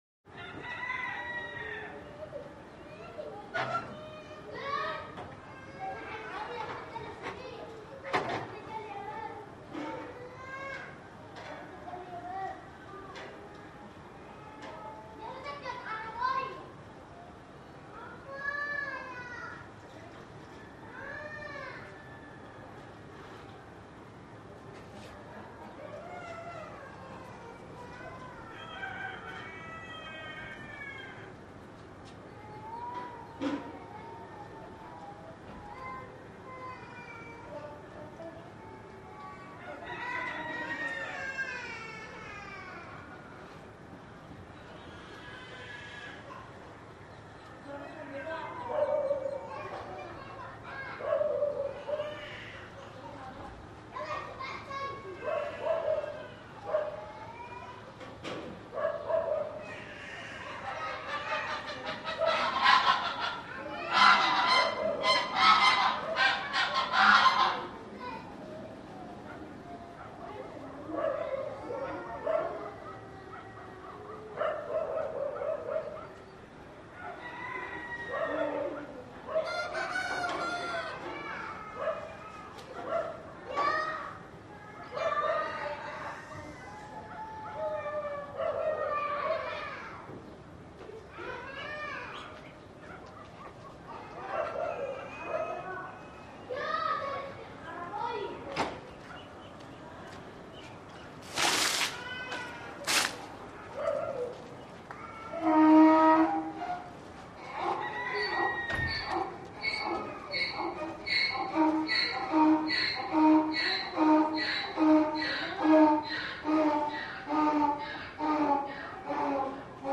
Abu-sir, Egypt - Bedouin Village Late Evening Atmosphere - Children Talking, Distant Dogs Barking, Baby Crying, Chickens, Geese, Throw Water In Dirt Street, Donkey Braying- Ambience, Middle East